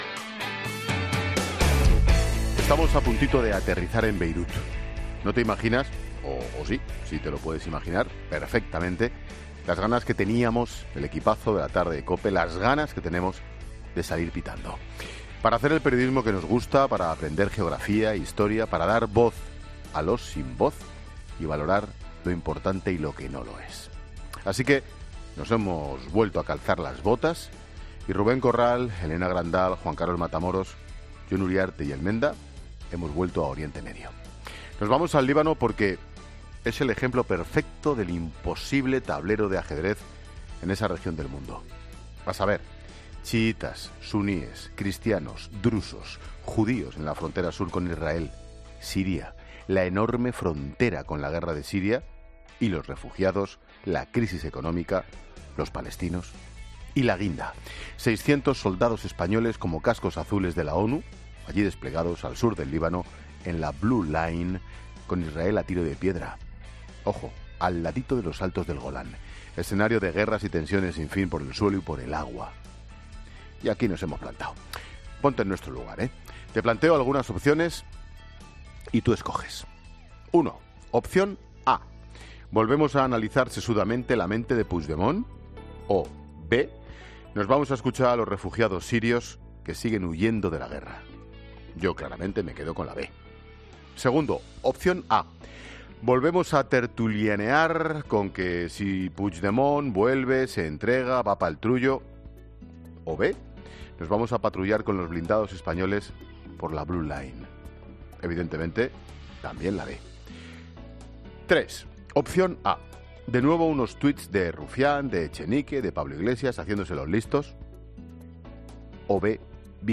Monólogo de Expósito
El comentario de Ángel Expósito antes de aterrizar en Líbano.